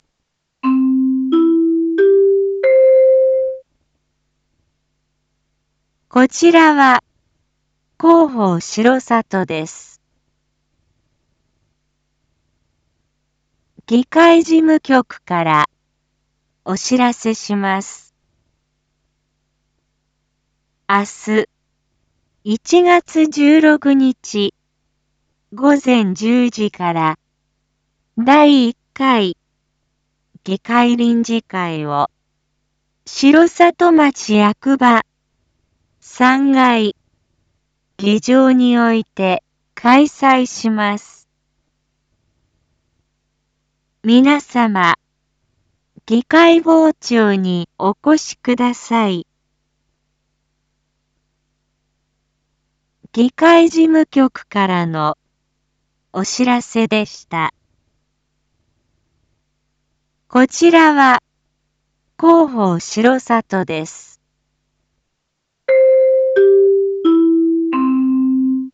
Back Home 一般放送情報 音声放送 再生 一般放送情報 登録日時：2026-01-15 19:01:10 タイトル：①第１回議会臨時会 インフォメーション：こちらは広報しろさとです。